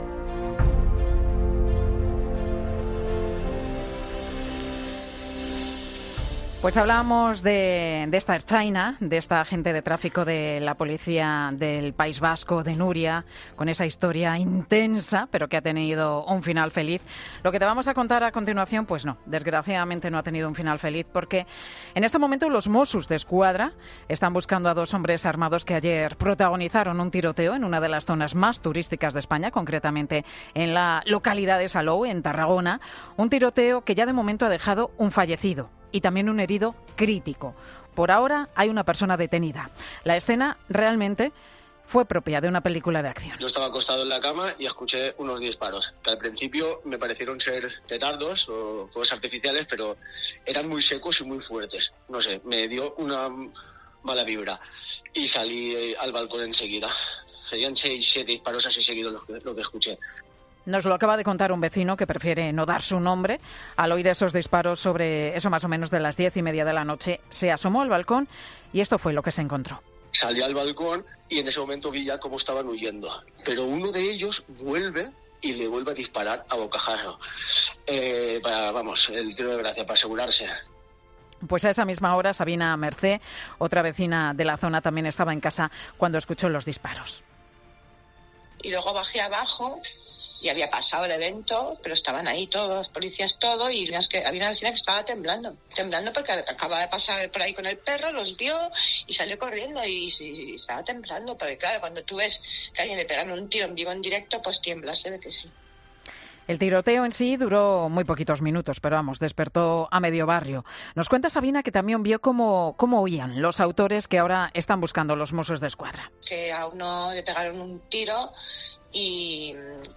Duro relato de los testigos del tiroteo de Salou en 'Mediodía COPE'